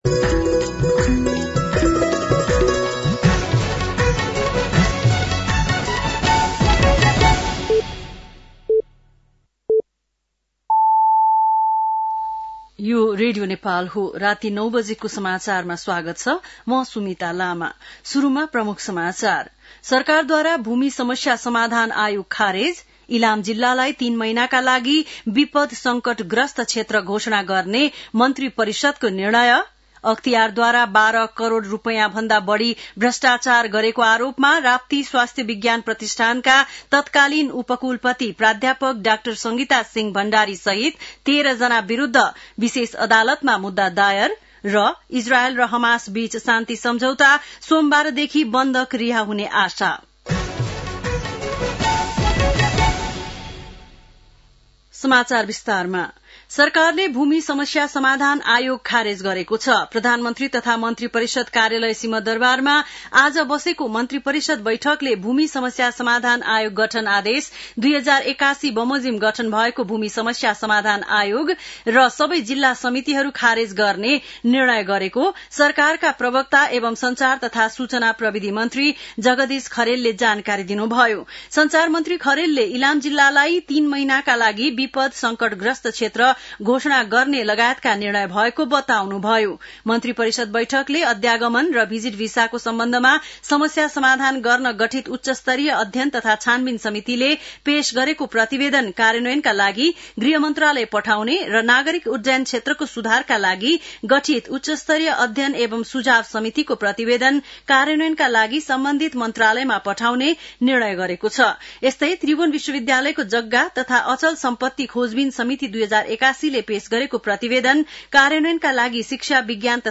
बेलुकी ९ बजेको नेपाली समाचार : २३ असोज , २०८२
9-PM-Nepali-NEWS-.mp3